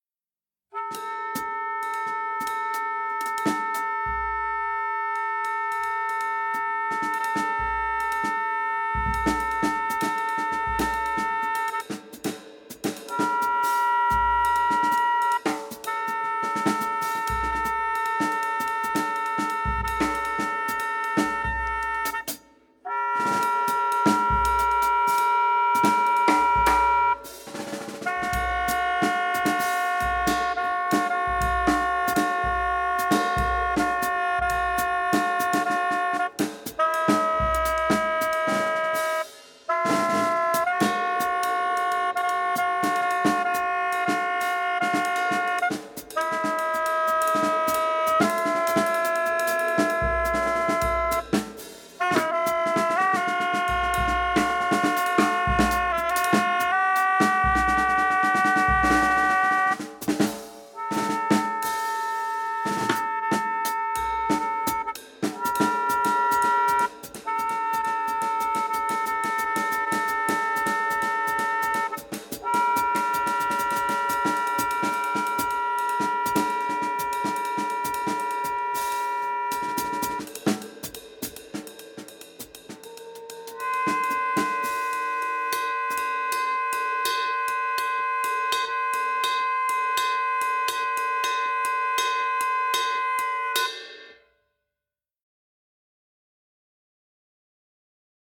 Plattform für improvisierte Kunst
Saxophone
Schlagzeug